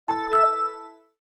Logon.wav